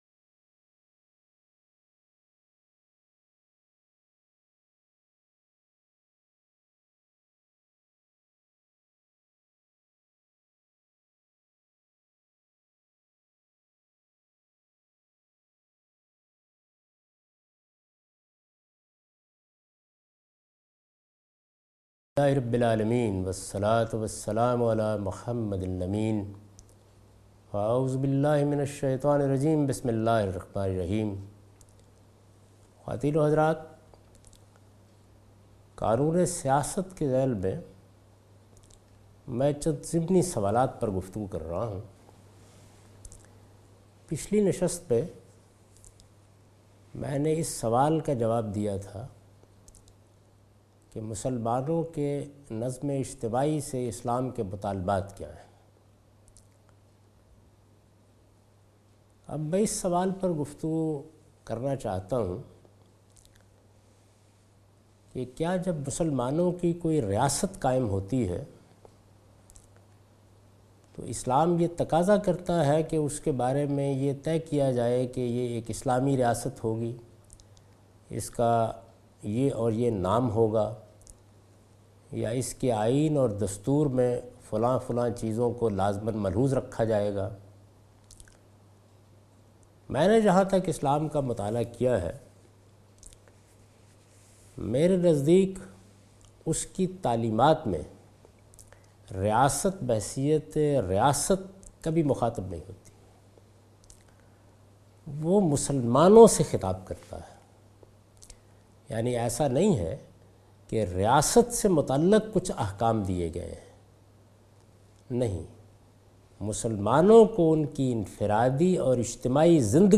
A comprehensive course on Islam, wherein Javed Ahmad Ghamidi teaches his book ‘Meezan’.
In this lecture he teaches the topic 'The Political Shari'ah' from 2nd part of his book. This sitting contains discussion on how government will be run in an Islamic socitey according to Quran and Sunnah.